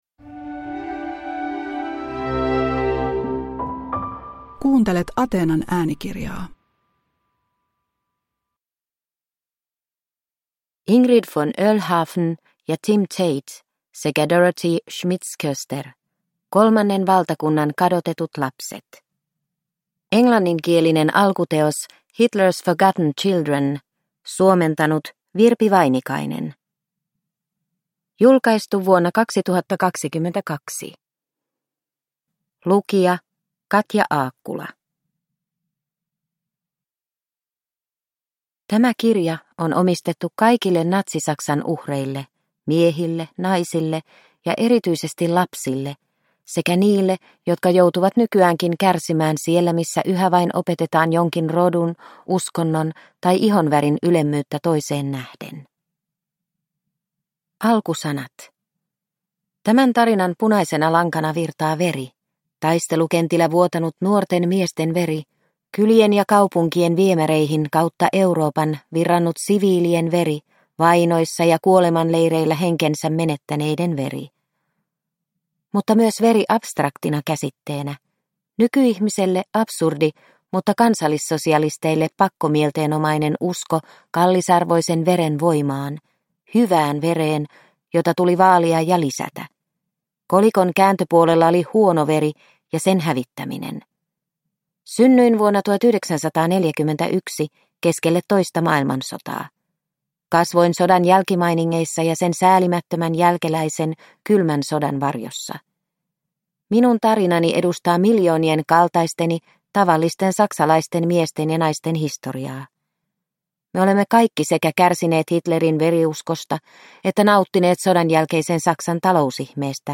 Kolmannen valtakunnan kadotetut lapset – Ljudbok – Laddas ner